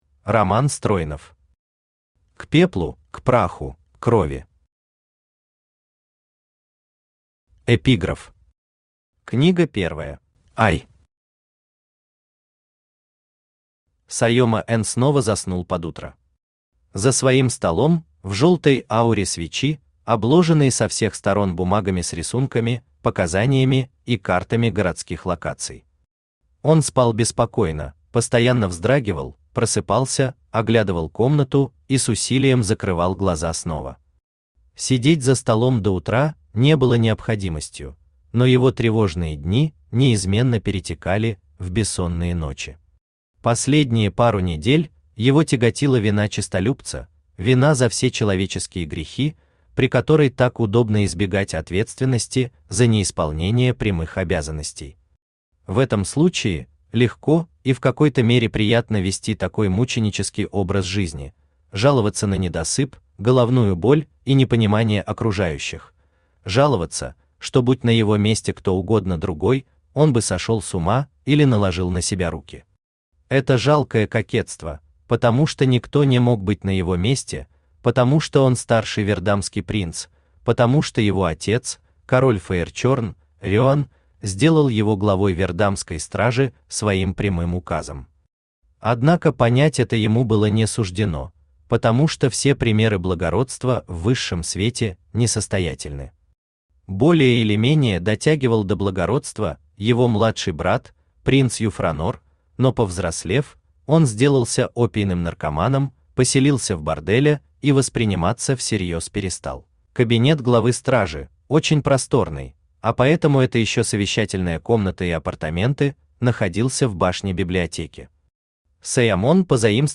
Аудиокнига К пеплу, к праху, к крови | Библиотека аудиокниг
Aудиокнига К пеплу, к праху, к крови Автор Роман Стройнов Читает аудиокнигу Авточтец ЛитРес.